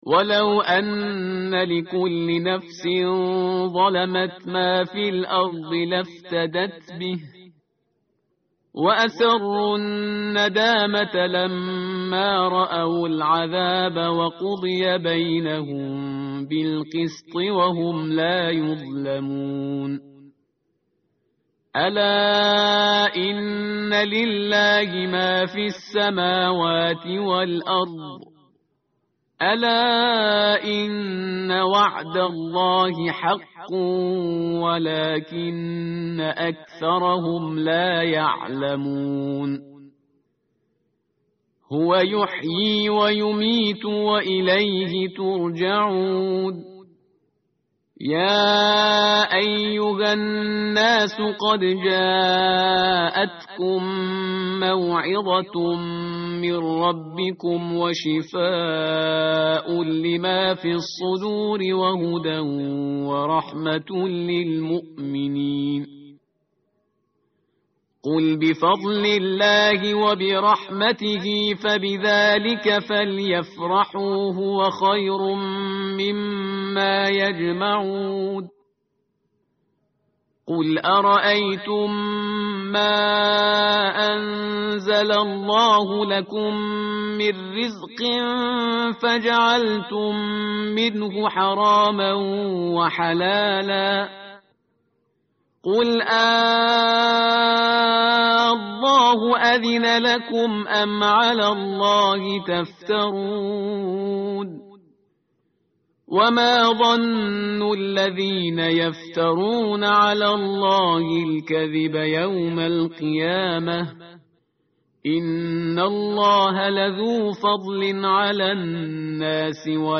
متن قرآن همراه باتلاوت قرآن و ترجمه
tartil_parhizgar_page_215.mp3